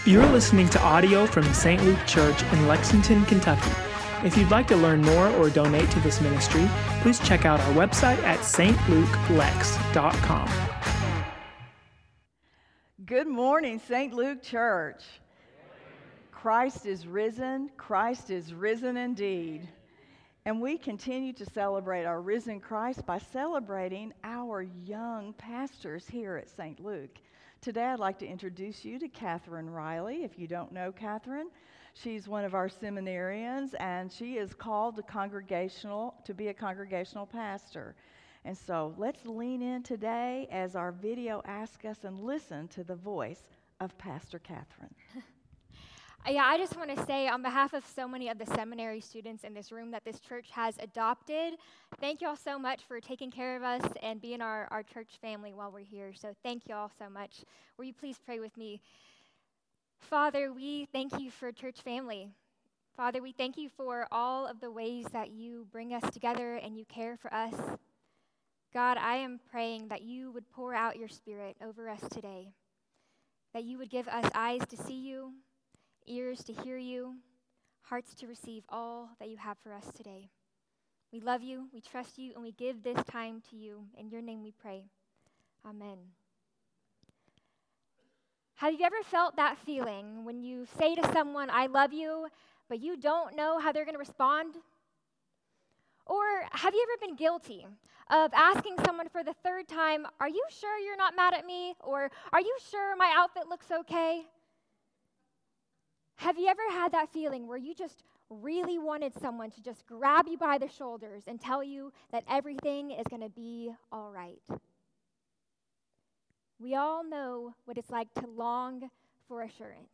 St. Luke Church Lexington – Sermons & Teachings ”How Can I Know?”